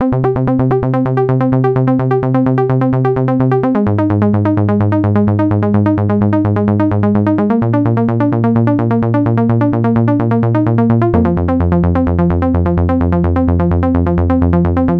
The filter was dropped a touch, some resonance added and a basic decay based filter envelope applied. I also raised the release of the amplitude envelope to make the sound a little ‘bigger’.
The edited patch plays back.